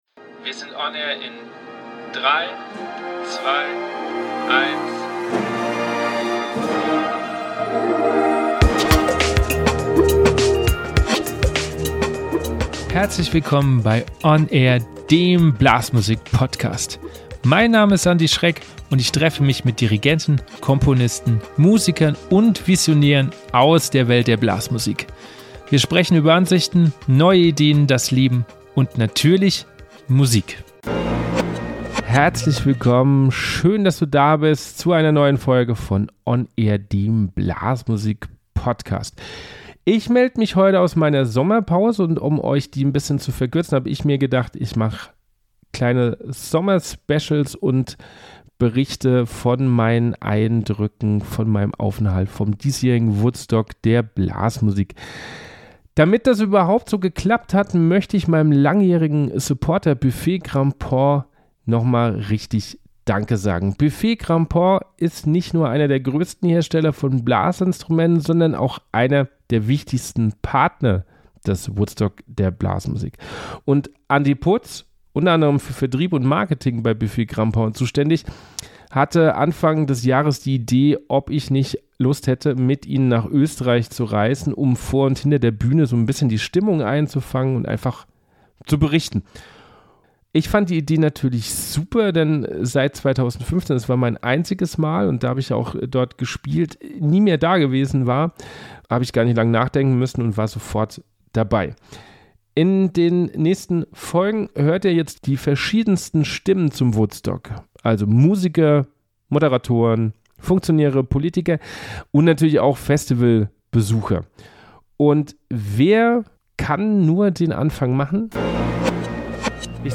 sommerspezial-woodstock-der-blasmusik-2022-teil-1-mmp.mp3